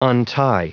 Prononciation du mot untie en anglais (fichier audio)
Prononciation du mot : untie